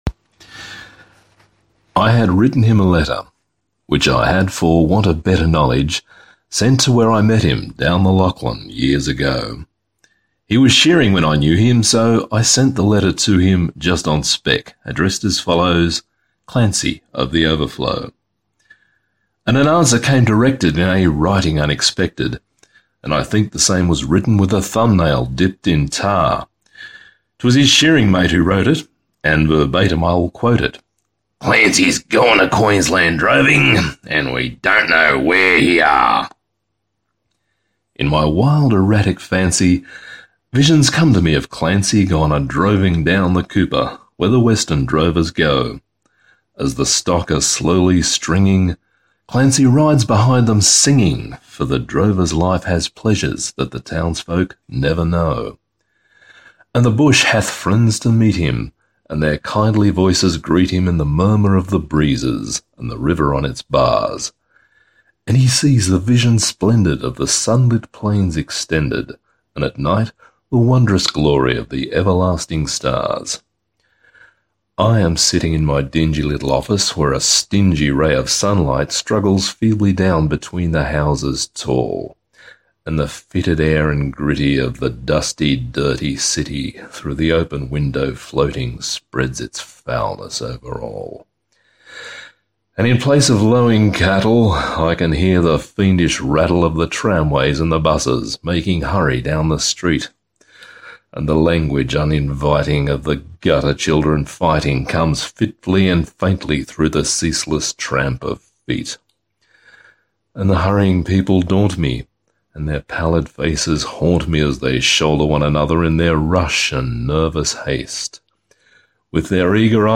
here (got to the last verse and tripped over one word, but what the hell).